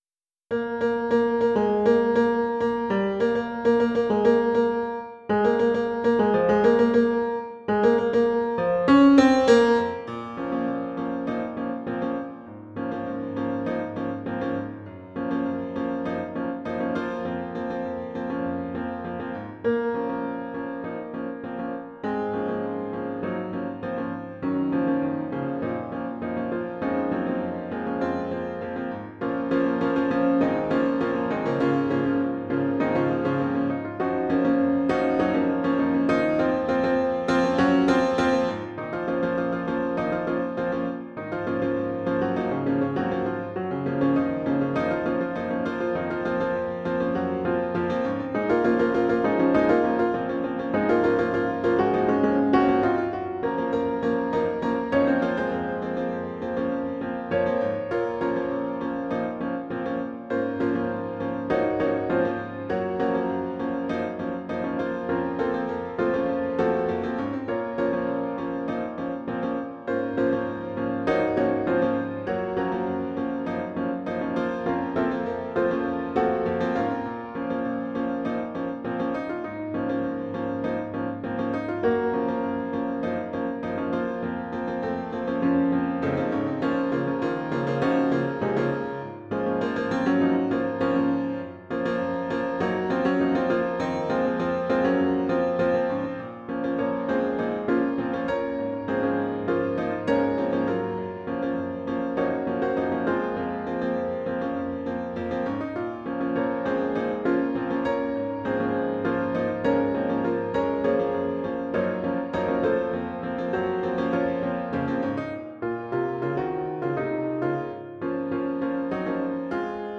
Title Finally I'm Me (Got the Keys) (SATB) Opus # 360 Year 2007 Duration 00:03:13 Self-Rating 4 Description I'd call this a very "American" song - about a rite of passage for teenagers. It's mostly aimed for performance by that age group, though an adult group could do it as a song about their own memories or whatnow. mp3 download wav download Files: mp3 wav Tags: Piano, Choral Plays: 1771 Likes: 0
360 Finally I'm Me SATB.wav